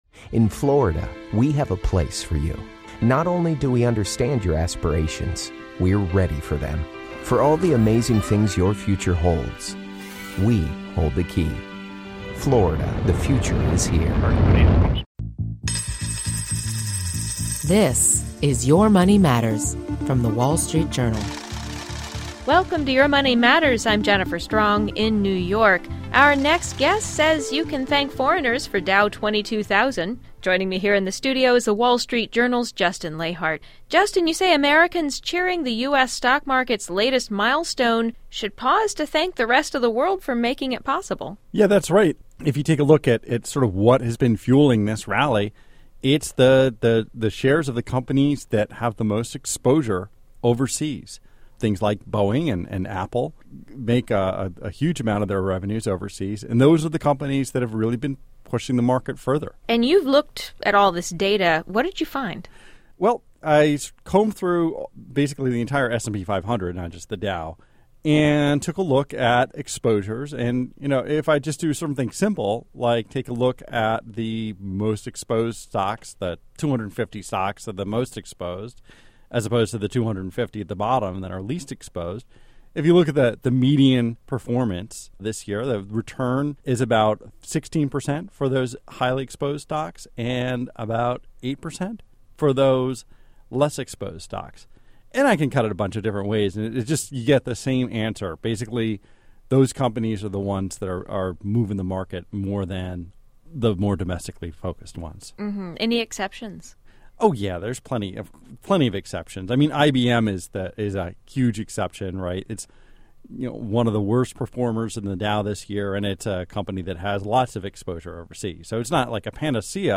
joins us in the studio